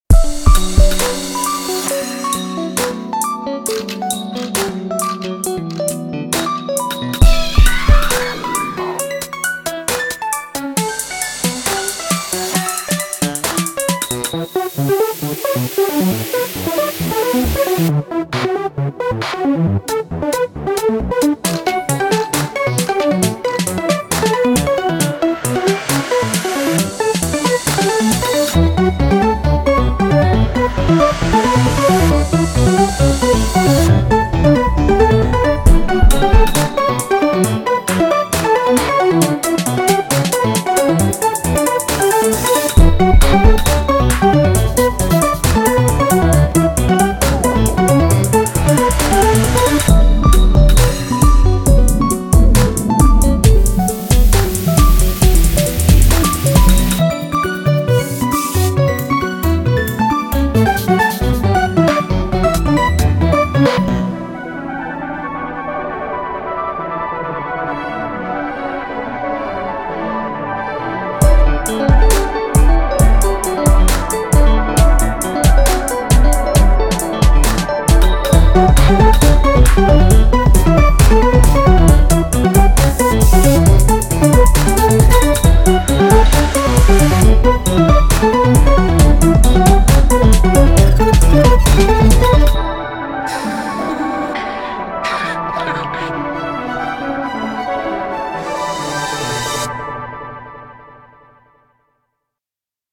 BPM67-67